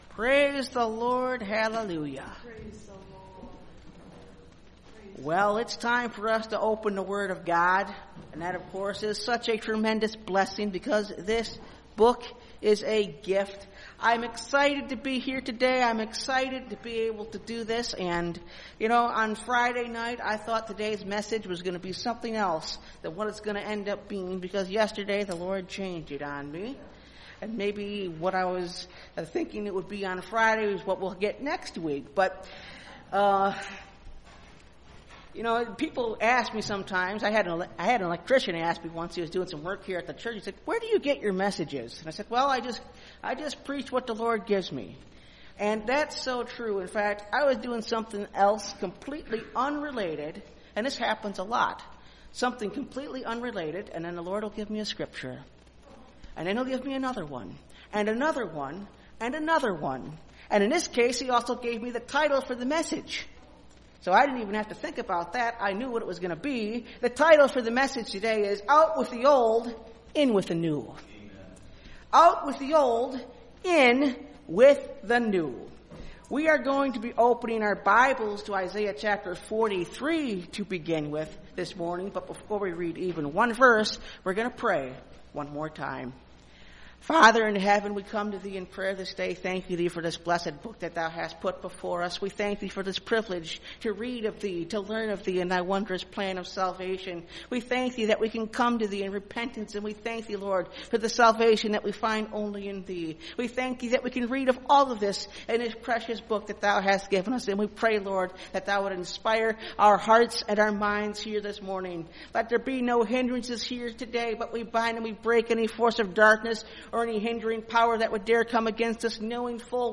Out With The Old, In With The New (Message Audio) – Last Trumpet Ministries – Truth Tabernacle – Sermon Library